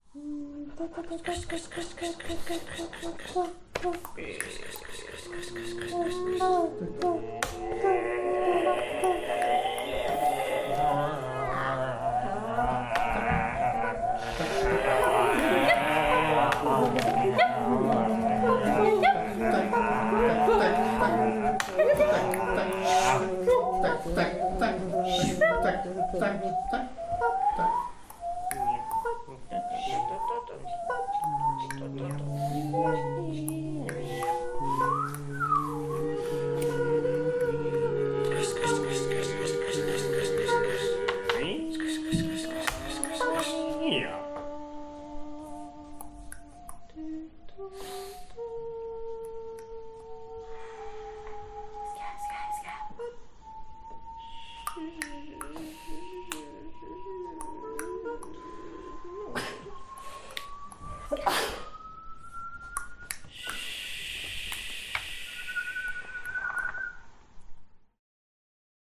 • Une séquence sonore produite avec la voix ou la bouche, l’appareil vocal.
L’atelier se déroule dans une salle de séminaire avec une grande table centrale, des chaises pour s’assoir autour, mais peu d’espace pour circuler ou faire de grands mouvements du corps.
Deuxième improvisation proposée par les animateurs : maintenant on a le droit de faire des variations autour de sa propre signature, soit en changeant des éléments (faire plus vite, faire plus long, plus fort, plus piano, etc.), soit en enrichissant, en ornementant avec d’autres éléments.
Improvisation 2. Durée : 1’09” (extrait) :